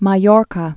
(mä-yôrkä, -lyôr-)